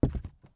ES_Walk Wood Creaks 11.wav